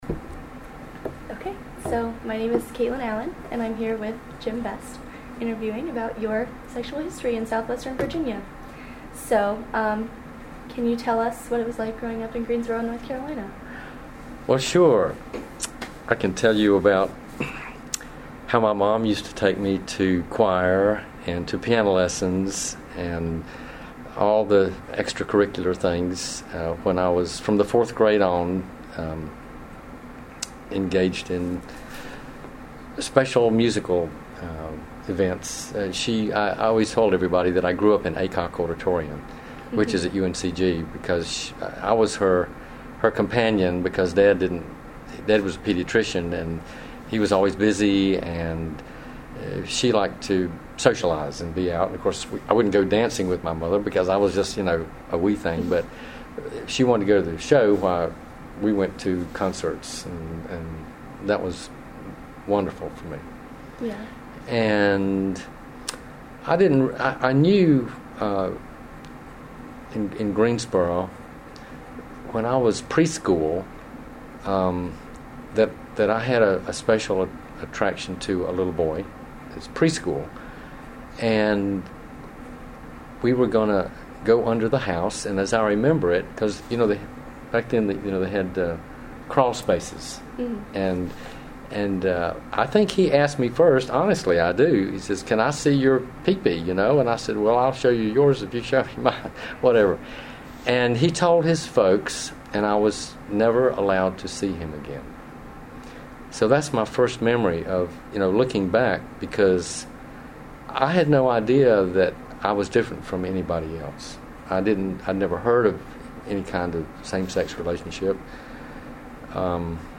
Location: Roanoke College, Miller Hall, 221 College Lane Salem, VA